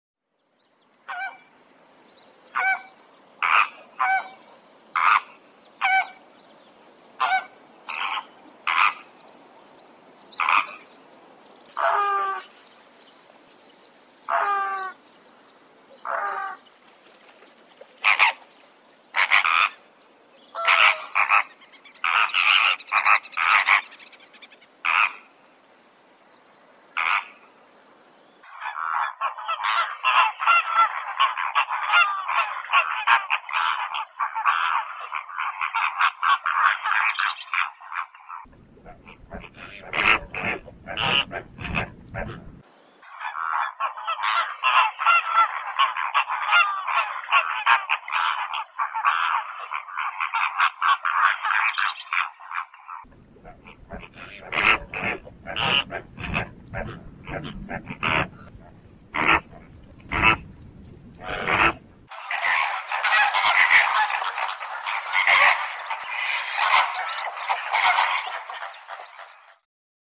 Fenicottero
FENICOTTERO-Phoenicopterus-roseus.mp3